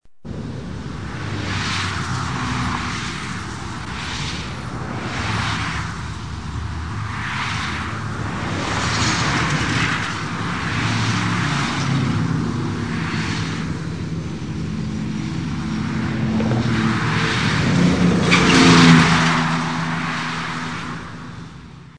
CARROS EN CARRETERA TRAFICO CARRETERA
Ambient sound effects
carros_en_carretera-trafico-carretera.mp3